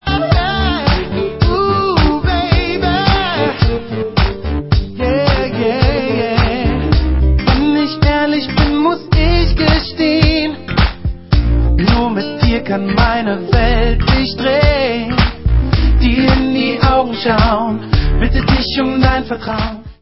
sledovat novinky v kategorii Pop